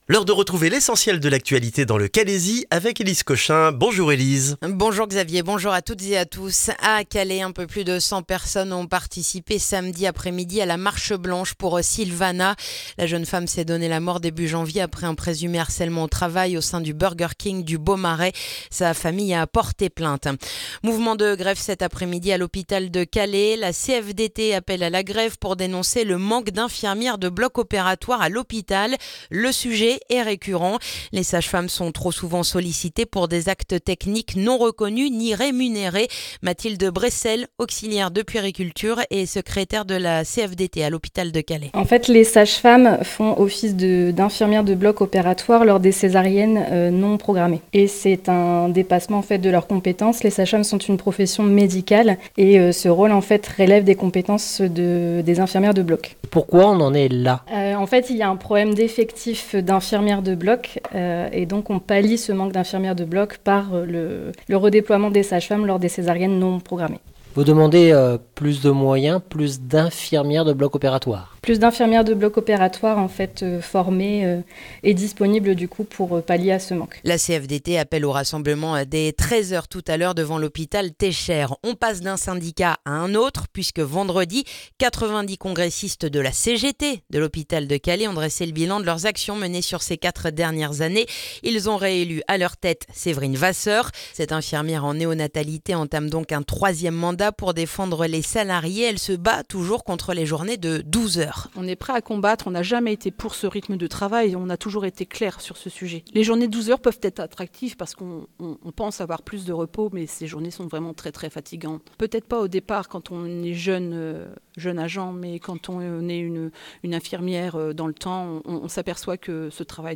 Le journal du lundi 9 février dans le calaisis